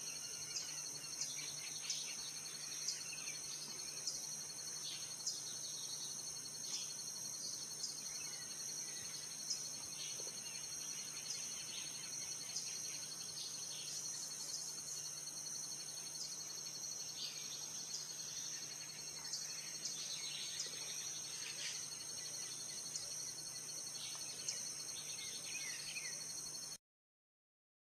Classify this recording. Dryocopus lineatusLineated WoodpeckerPicamaderos ListadoPic ouentou